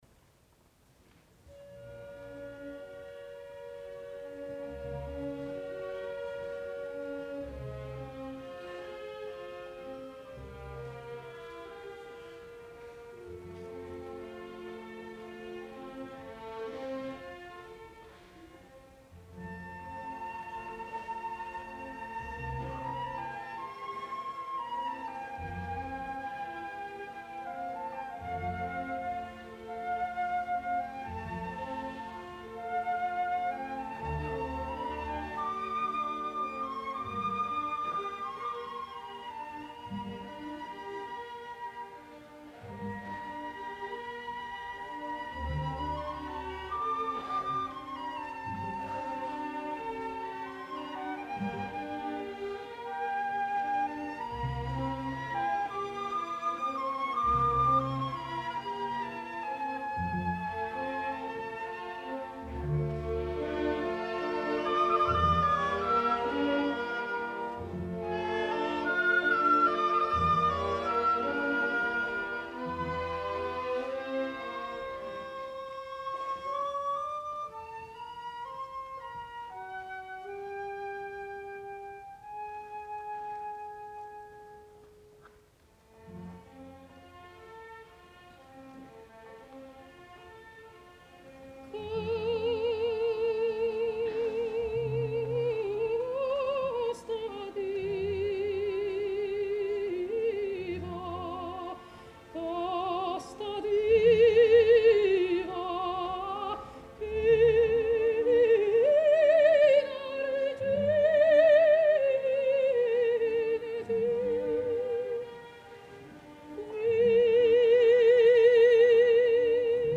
Genre : Classique